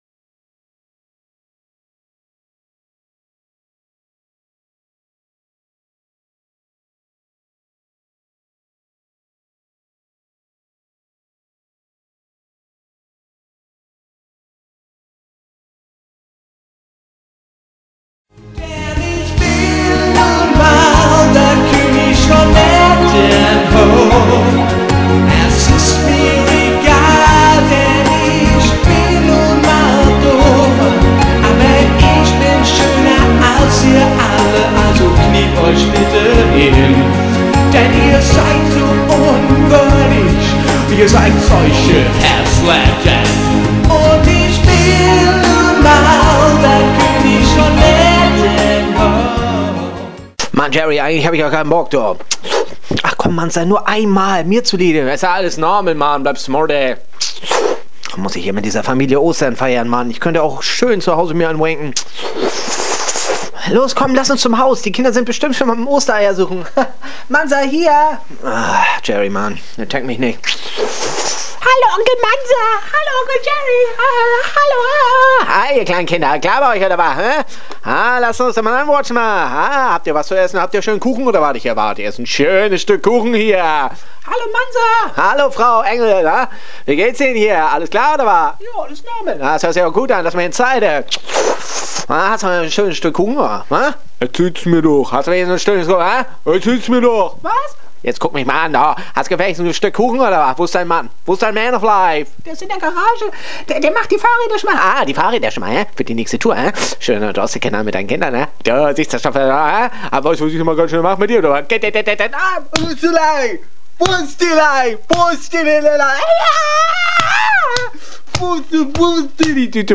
Osterhörspiel uncut (128kbit_AAC).m4a